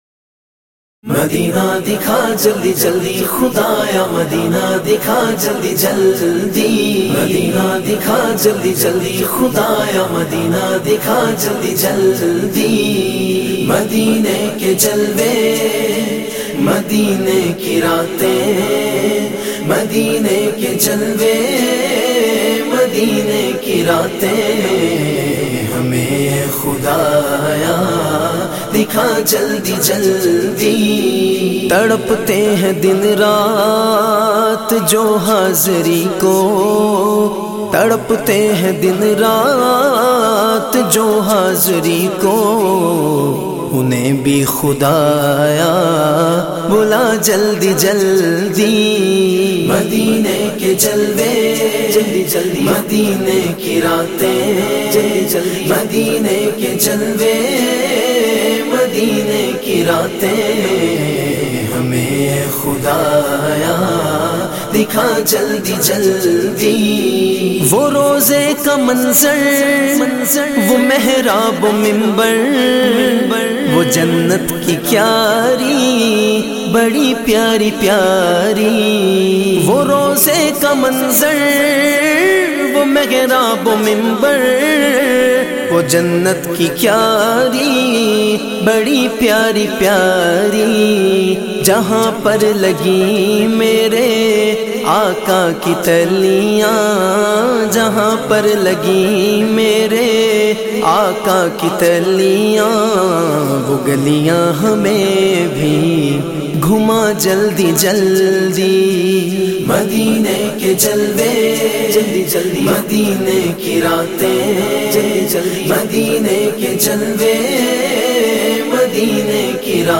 naat
in a Heart-Touching Voice